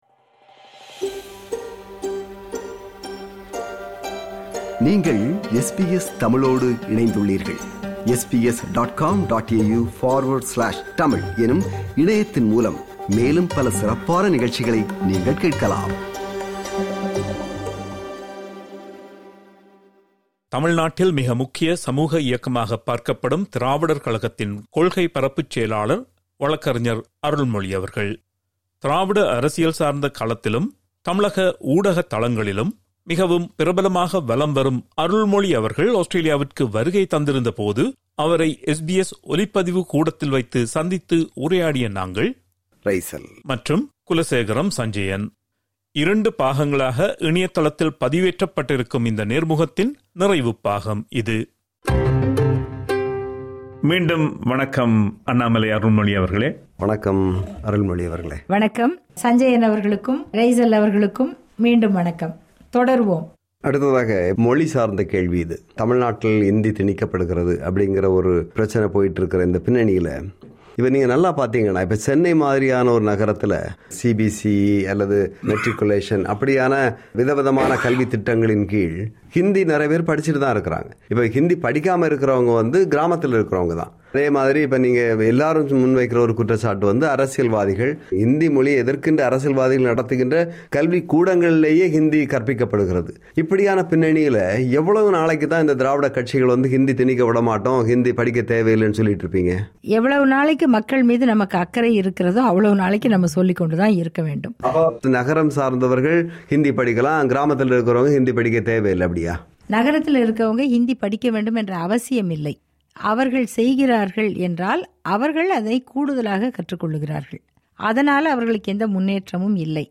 இரண்டு பாகங்களாக பதிவேற்றப்பட்டிருக்கும் நேர்முகத்தின் நிறைவுப்பாகம் இது.